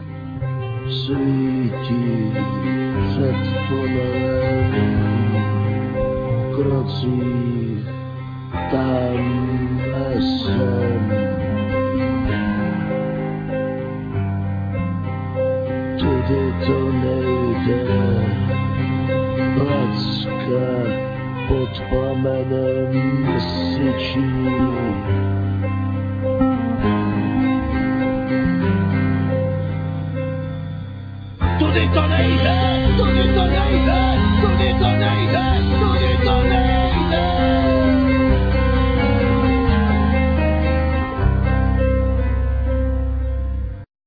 Piano,Keyboards,Trumpet,Vocal,whistling
Drums,Accordion,Vocal
Bass guitar
Alt sax,Clarinet
Cello
Cimbal